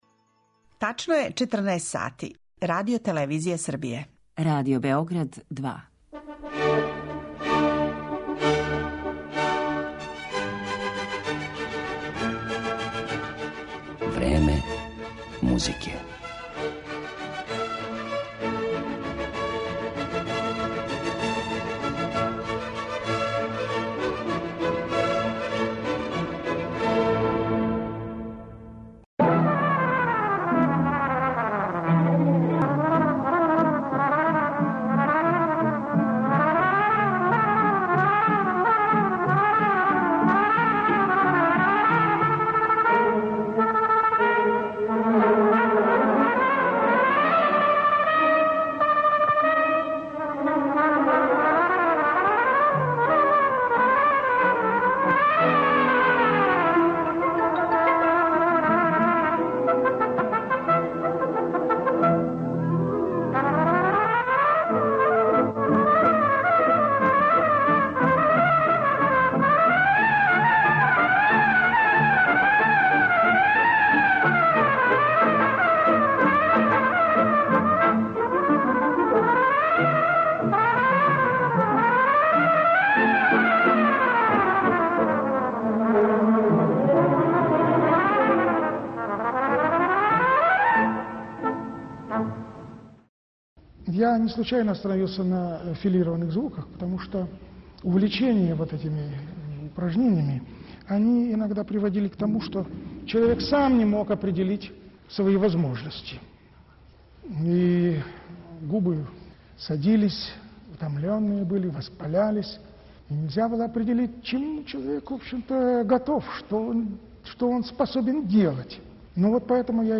Чућете причу о његовој извођачкој и педагошкој каријери, као и одабране снимке његових неупоредивих интерпретација.